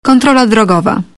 Dźwięki ostrzegawcze Punkt kontroli drogowej
punkt-kontroli-drogowej-36